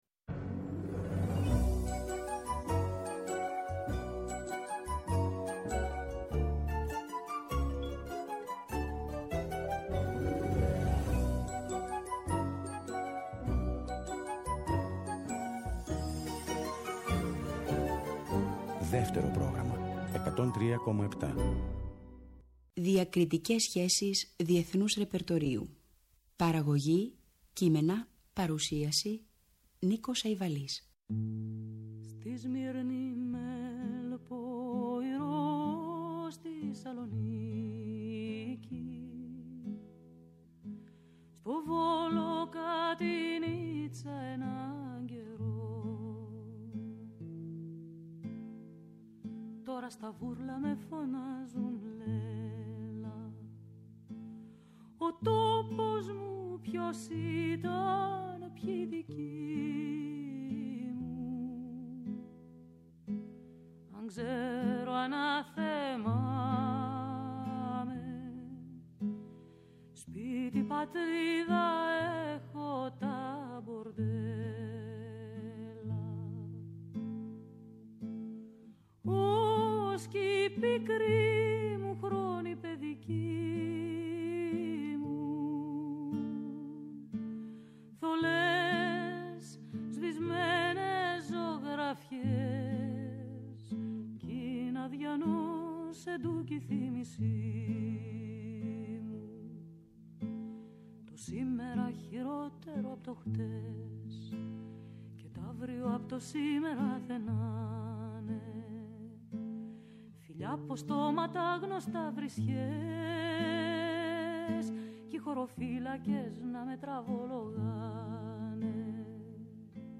Μία ώρα με τραγούδια που την μουσική τους έγραψαν αγαπημένες Ελληνίδες συνθέτριες, τραγουδοποιοί ή/και ερμηνεύτριες της εποχής μας. Θα απολαύσουμε συνθέσεις τους ορχηστρικές και τραγούδια -μελοποιήσεις ποιημάτων, με στίχους άλλων αλλά και δικούς τους- που τραγουδούν οι ίδιες.